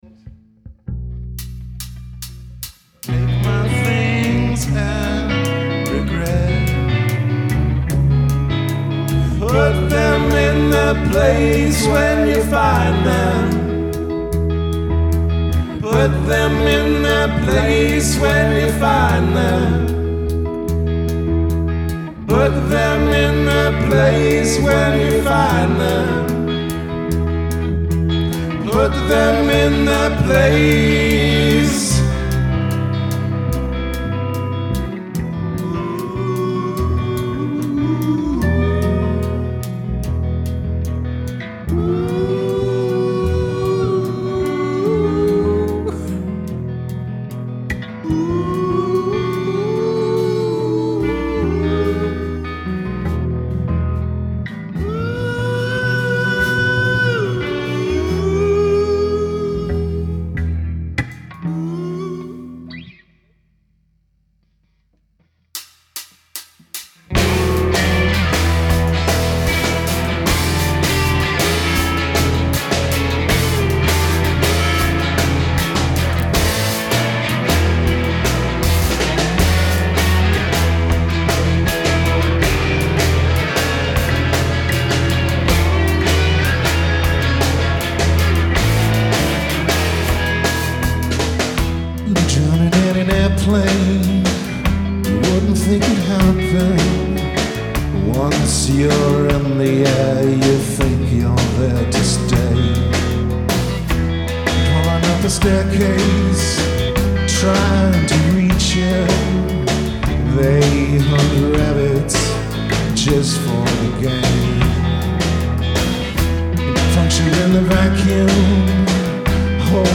2011_LIVE_SETUP_KEYS_2_R1_airplane_mx2.mp3